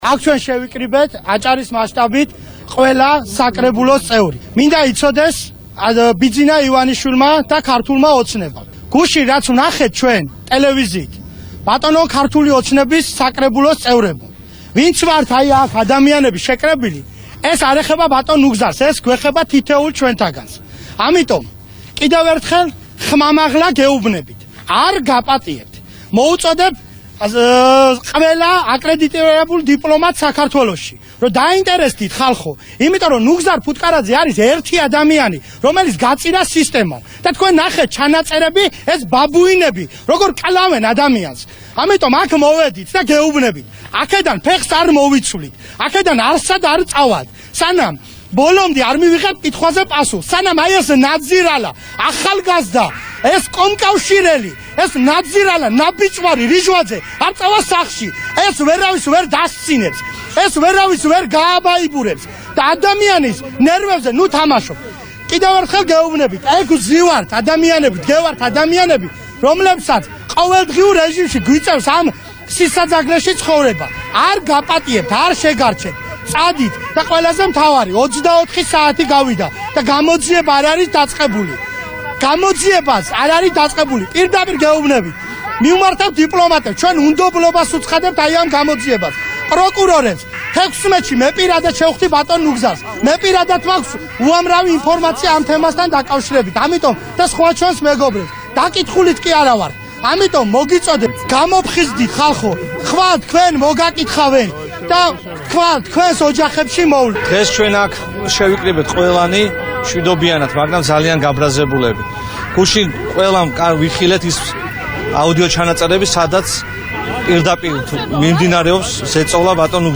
ბათუმში ოპოზიციური პარტიების საპროტესტო აქცია მიმდინარეობს.
აქციის მონაწილეების ხმა